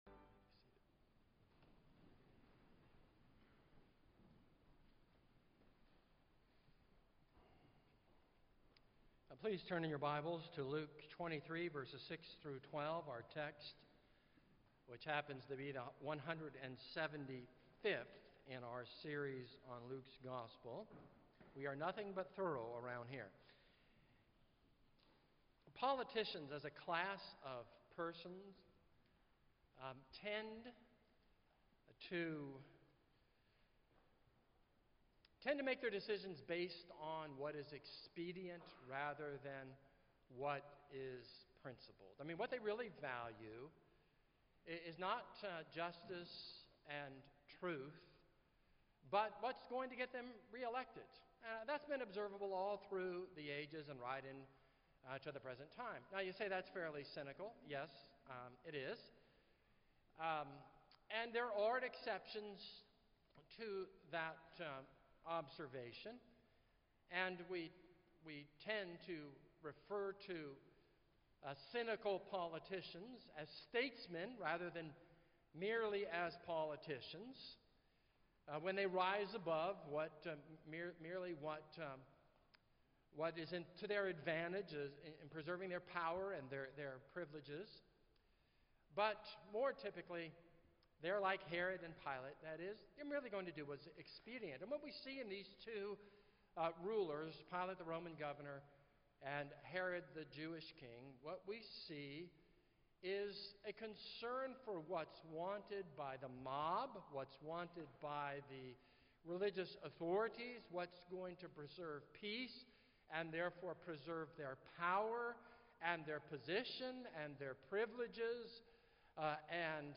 This is a sermon on Luke 23:6-12.